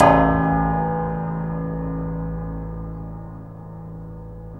SI2 PIANO02L.wav